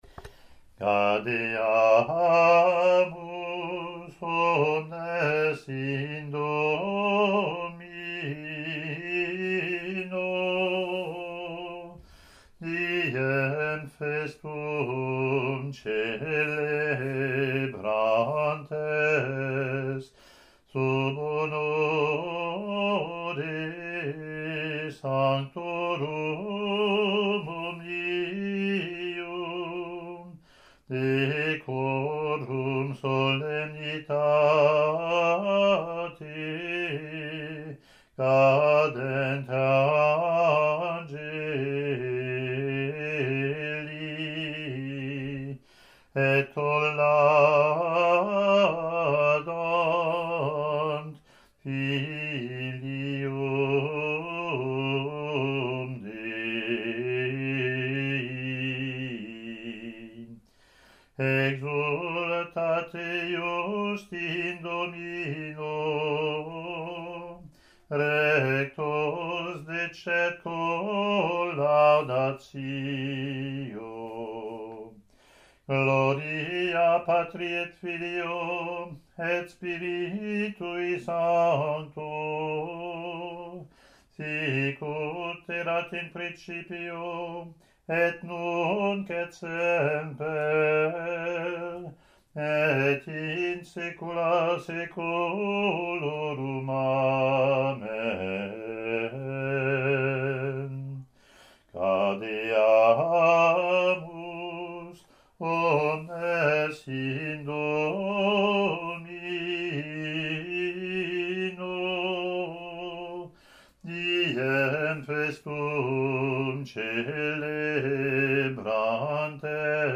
Latin antiphon and verse, )